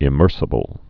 (ĭ-mûrsə-bəl)